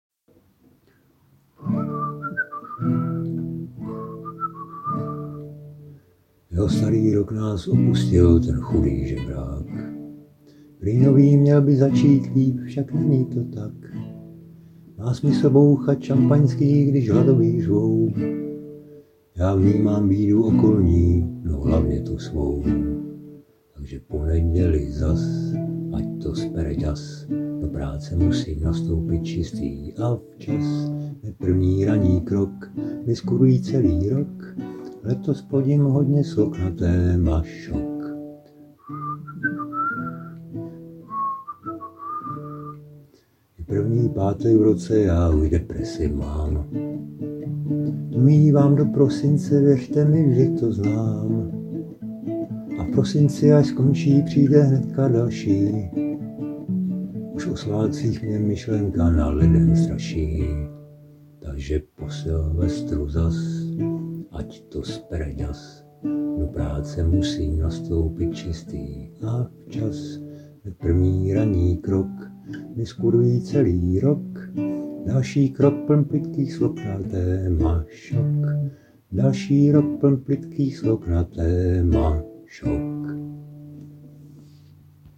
Humor
...je to blues...;-) ale dokud si hvízdáš, tak dobrý...